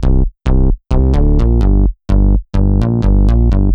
Index of /musicradar/french-house-chillout-samples/128bpm/Instruments
FHC_MunchBass_128-C.wav